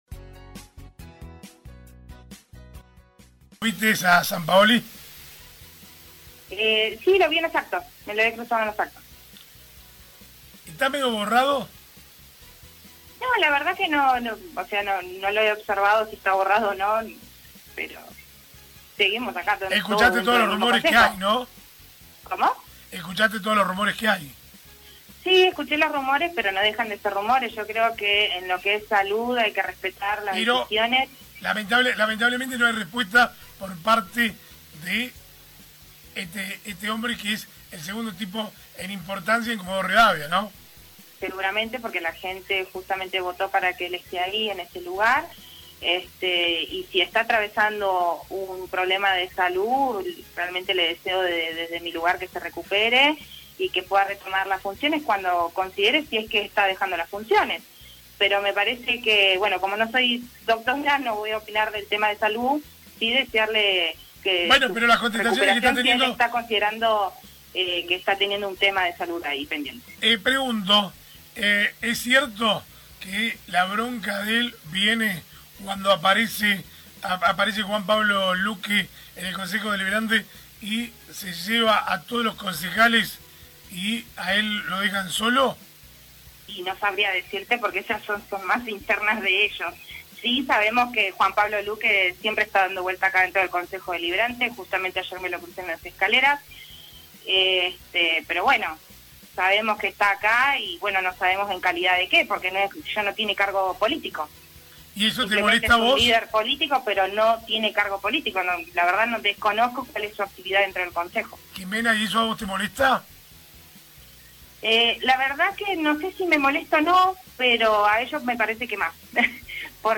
Finalizando la sesión, Sampaoli, en uso de la palabra, le hizo un fuerte llamado de atención coronado como “sugerencia”.